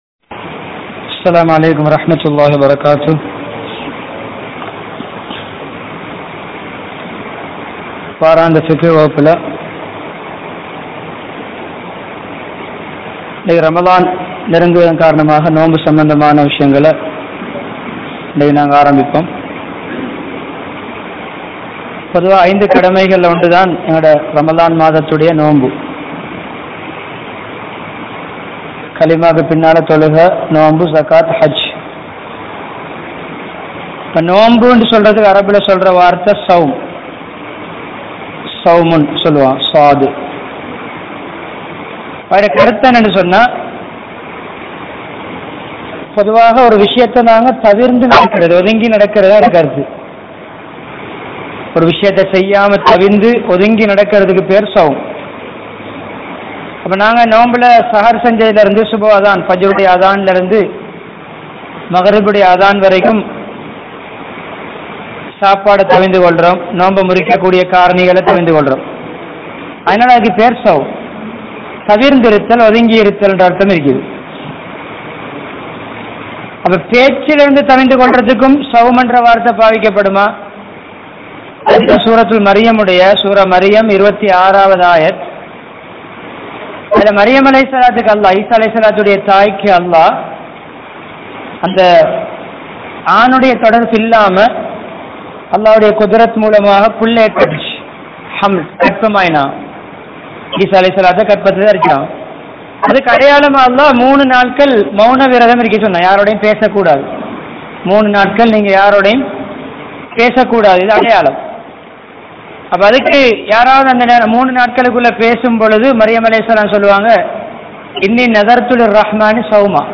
Ramalaanin Aarampa Naal (ரமழானின் ஆரம்ப நாள்) | Audio Bayans | All Ceylon Muslim Youth Community | Addalaichenai